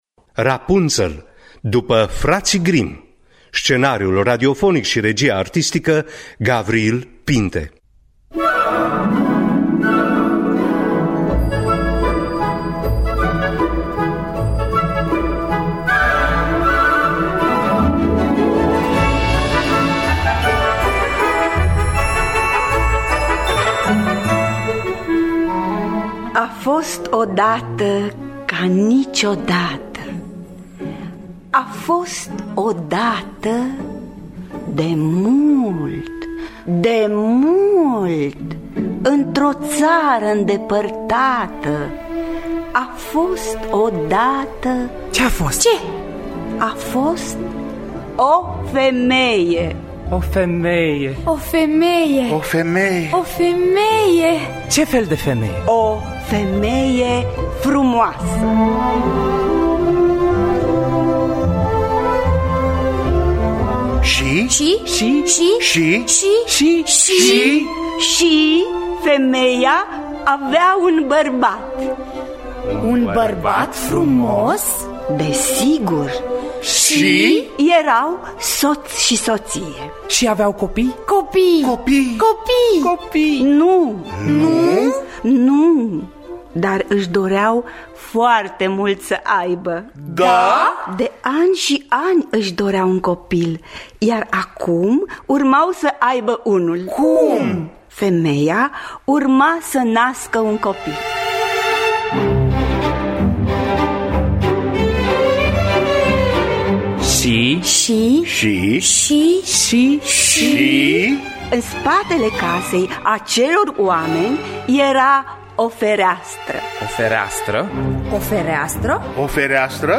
Rapunzel de Fraţii Grimm – Teatru Radiofonic Online